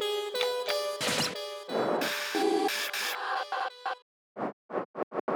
• 12 FX Loops: Add depth and dimension to your tracks with a variety of dynamic effects loops, perfect for transitions and creating atmospheric soundscapes.
3-FX-LOOP-179.wav